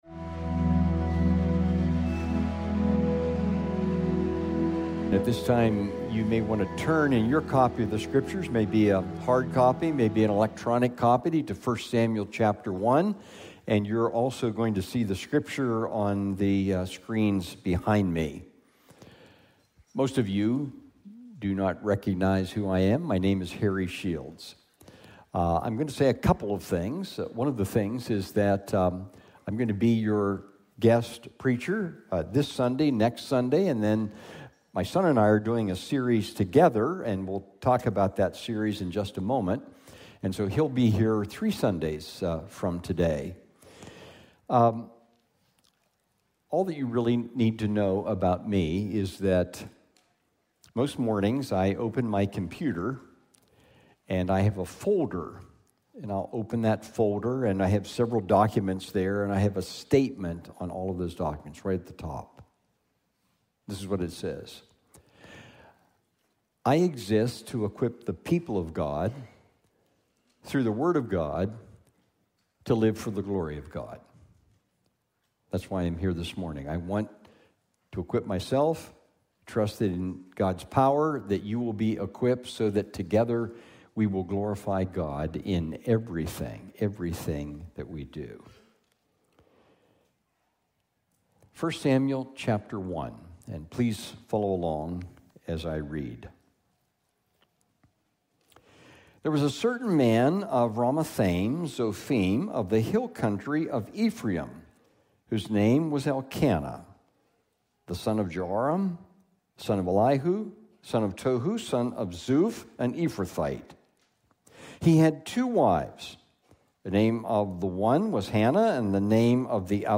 Village Church of Bartlett: Sermons Is God Really Real?